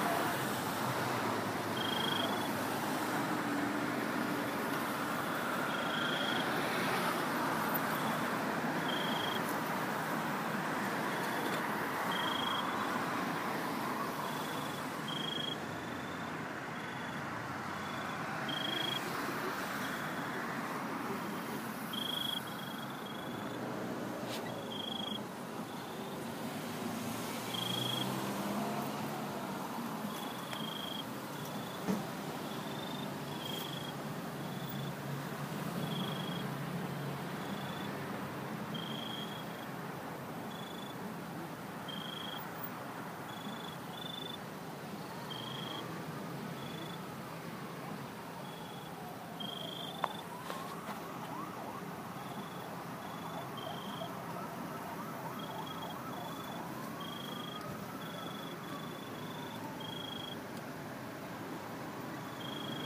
Crickets in Pawtucket, Rhode Island
Crickets recorded in a planter at the edge of a shopping plaza on a warm November evening. On the corner of Lafayette and East Ave, on the Providence / Pawtucket city line, Rhode Island USA.